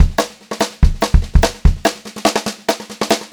144SPBEAT4-R.wav